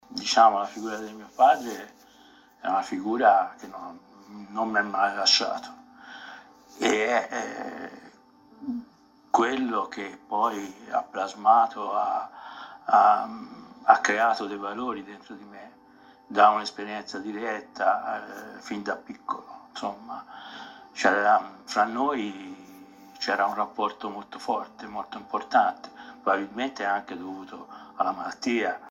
Ascoltiamo un estratto.